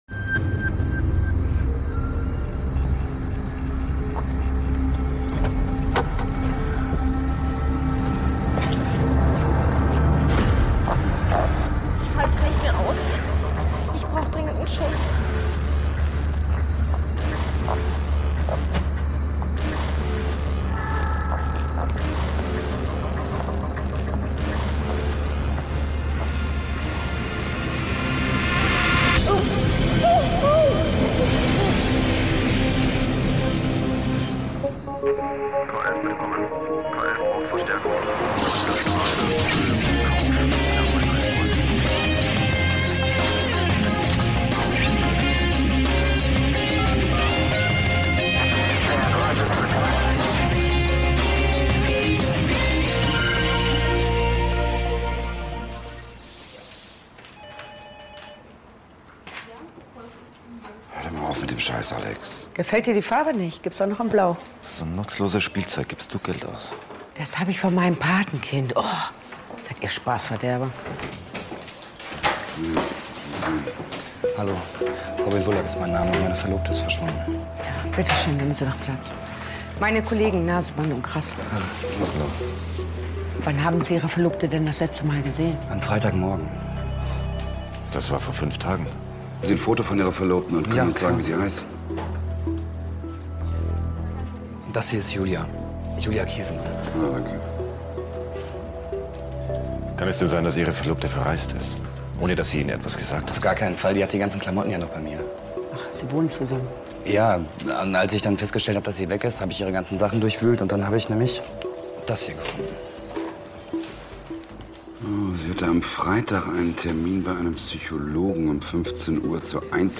Therapiegruppe des Schreckens - K11 Hörspiel